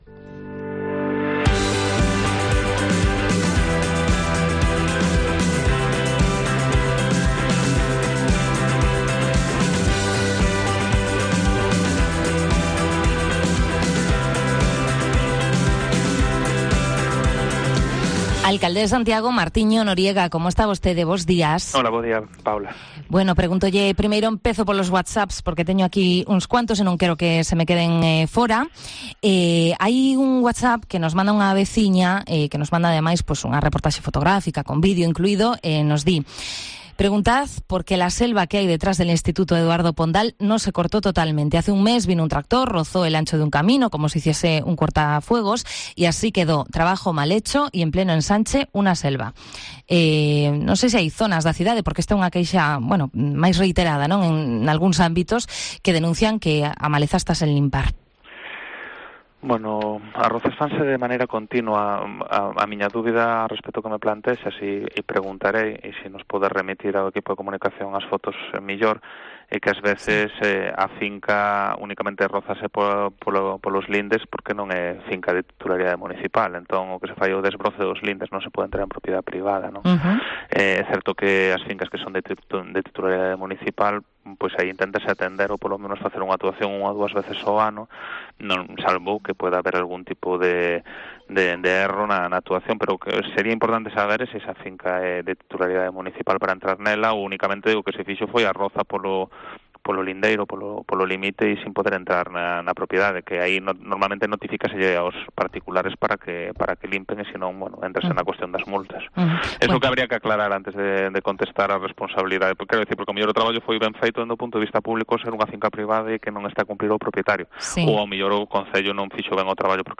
Repaso a la actualidad de Santiago este martes con el alcalde, Martiño Noriega, en Cope+Santiago.
El regidor municipal respondió a muchas preguntas que nos trasladaron los oyentes sobre los temas más variados... desde la falta de alumbrado de Navidad en algunos barrios a las quejas por averías en los paneles informativos del bus urbano o fincas que están sin desbrozar.